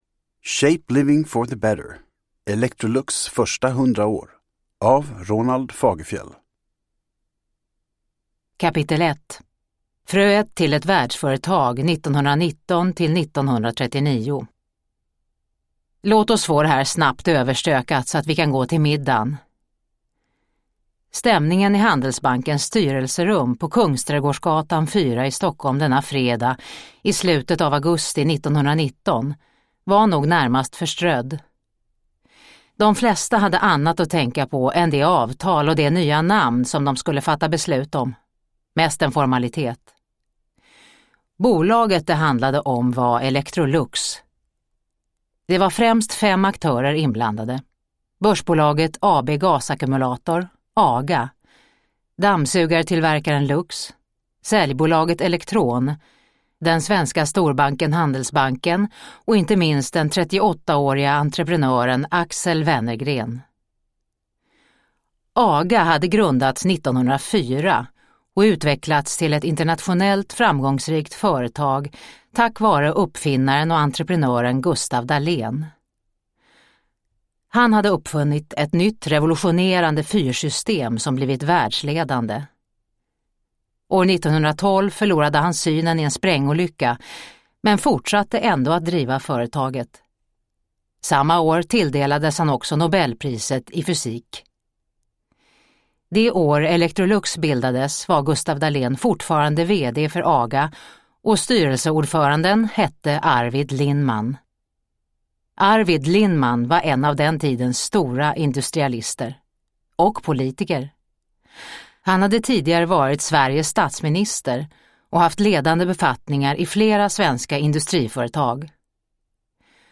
Shape living for the better : Electrolux första 100 år – Ljudbok – Laddas ner